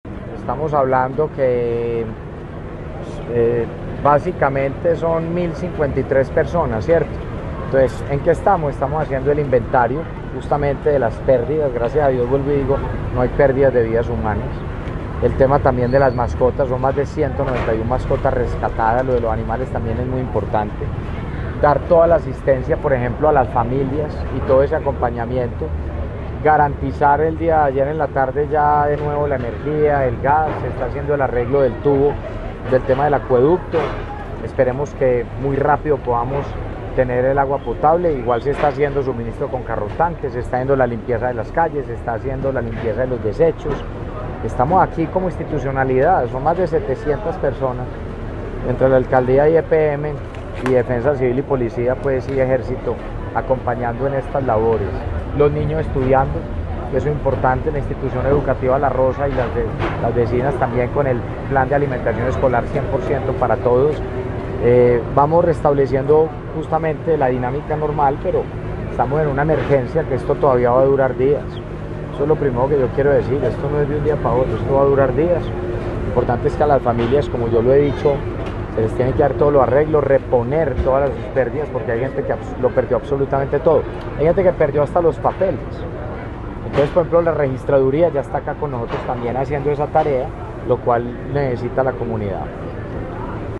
Palabras de Federico Gutiérrez, alcalde de Medellín El Puesto de Comando Unificado reportó como novedad hoy martes, 12 de noviembre, un incidente en la zona afectada por la inundación en el sector El Sinaí, en la comuna 2-Santa Cruz.